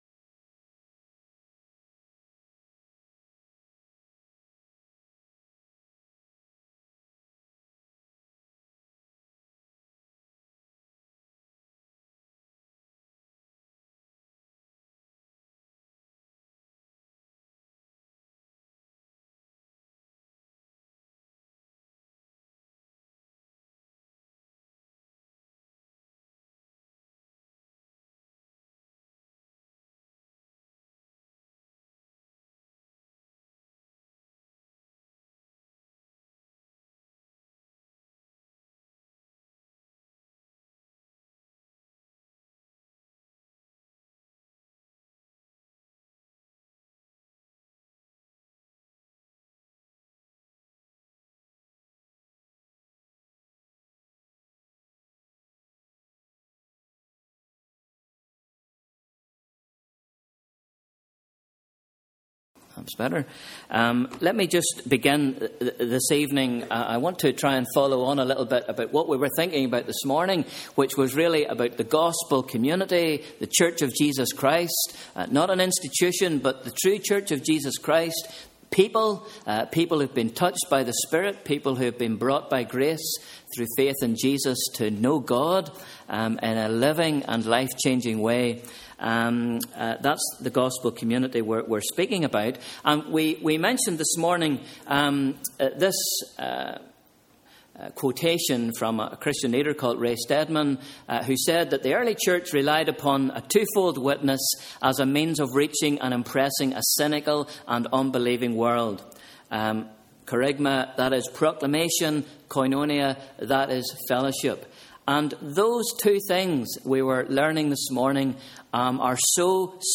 Sunday 8th December 2013 – Evening Service